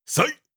Effort Sounds
24. Effort Grunt (Male).wav